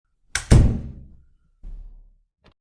DoorCloseSoundEffect.wav